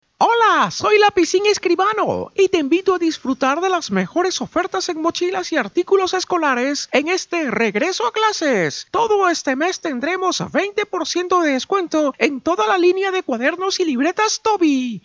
spanisch Südamerika
Sprechprobe: Sonstiges (Muttersprache):
corto caracterizacion.mp3